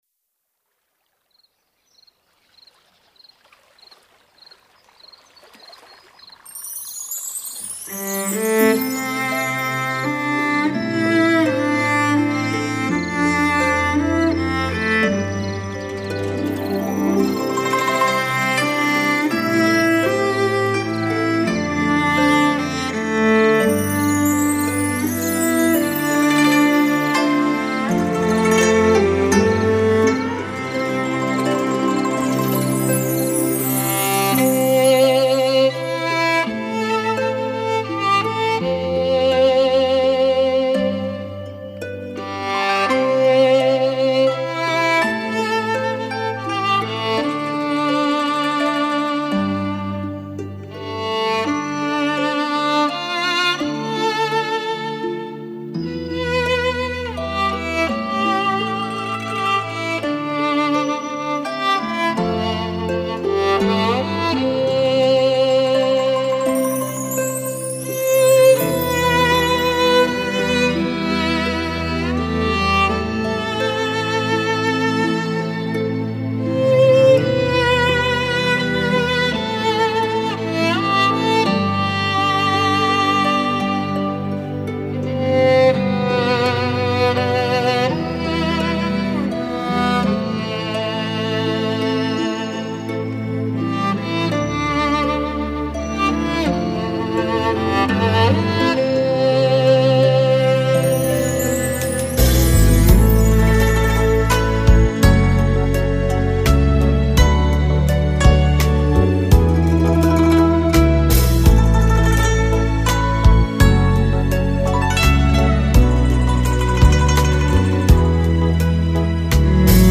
柔情小提琴的万种风情
西乐与中乐遇见之美
传统与现代触碰之醉